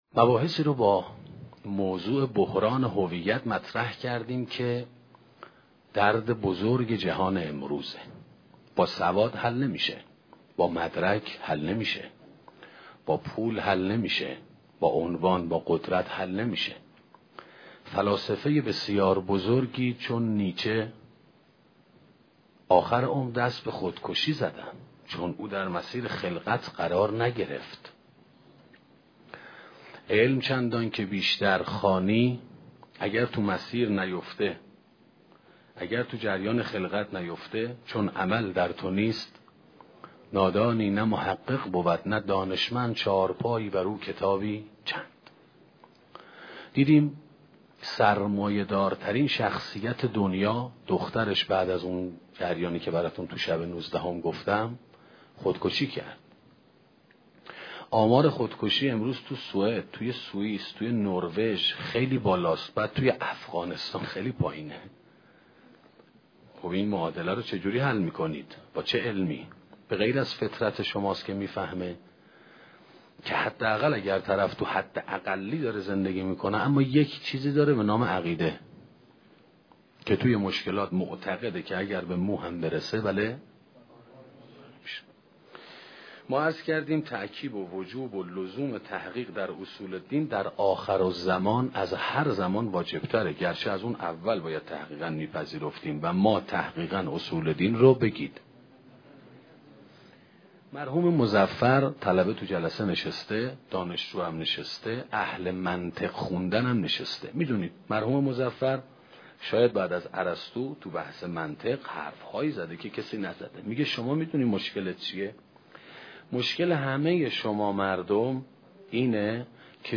سخنرانی درد مقدس - موسسه مودت
sokhanrani-dardMoghaddas.mp3